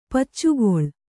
♪ paccugoḷ